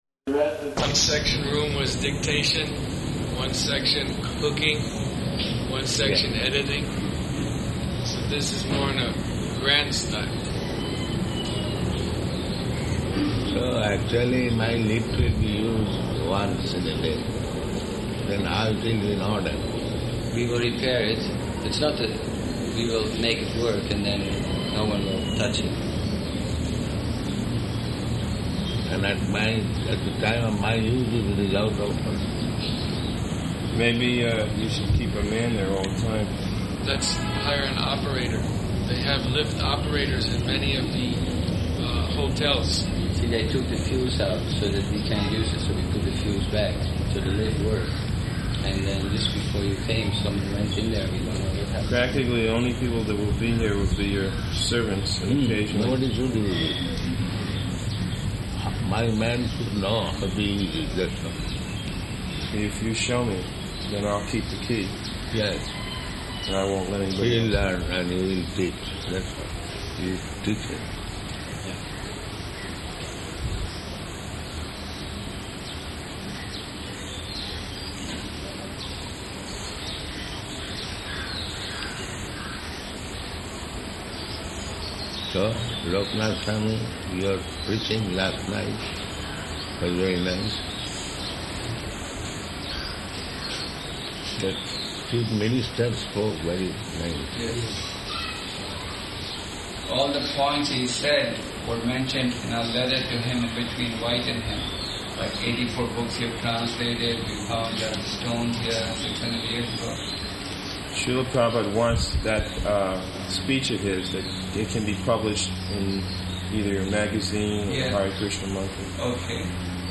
Type: Conversation
Location: Bombay